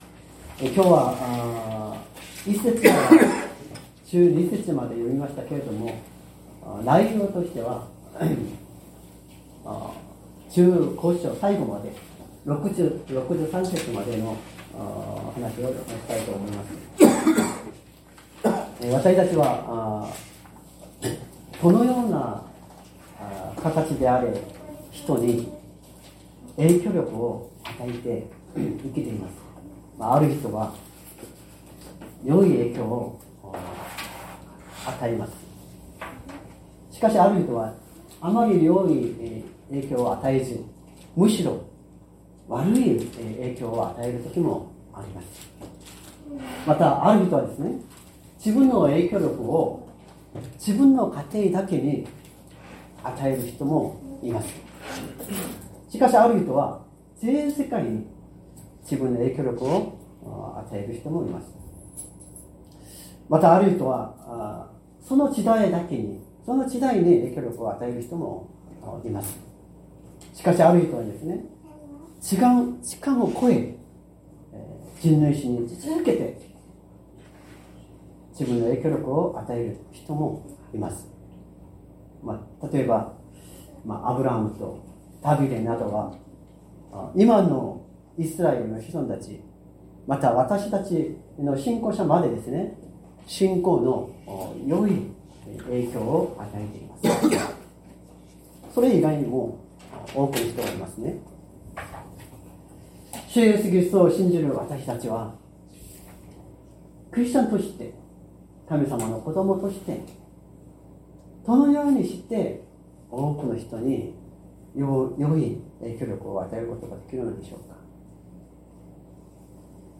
善通寺教会。説教アーカイブ 2025年01月19日朝の礼拝「影響力を与える人生」